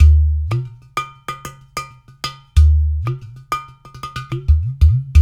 93 -UDU 08L.wav